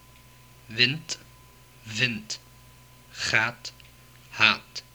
Below is a recording of minimal pair words in Dutch that prove that these sounds exist in Dutch as phonemes, not allophones.
While there are more consonants than this in Dutch, these were the ones present in the word list recordings from the UCLA phonetics lab archive.